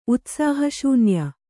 ♪ utsāha śunya